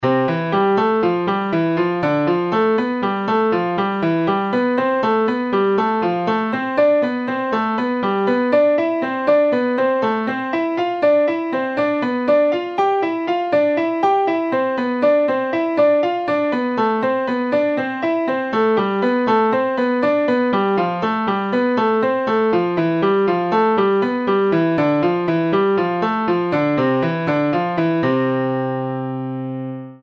La musique jouée par un logiciel est à titre indicatif.
hanon_ex08.mp3